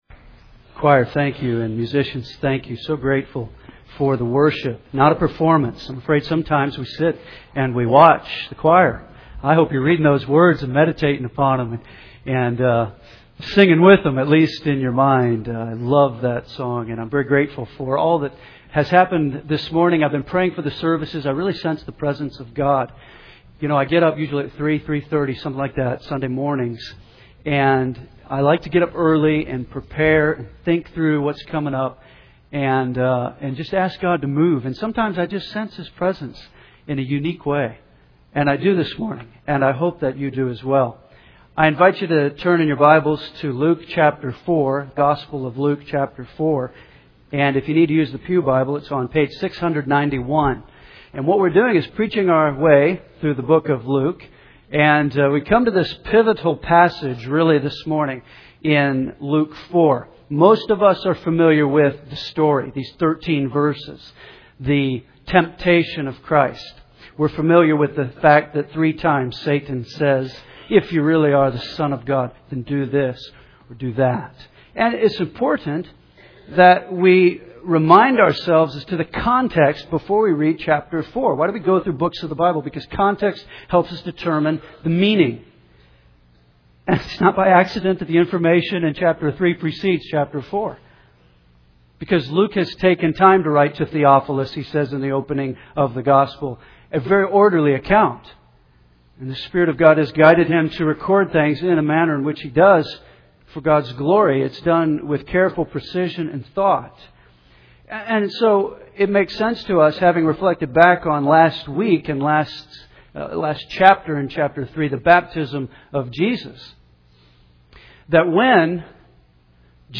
Henderson’s First Baptist Church, Henderson KY